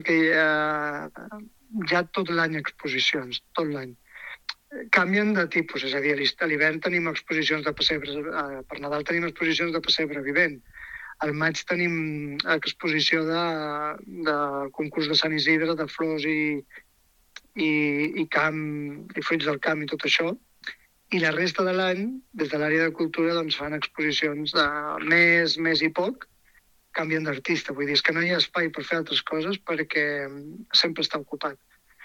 Josep Amat considera que la ciutadania «ja coneix l’espai» i està funcionant molt bé, amb exposicions de diferents artistes i gèneres.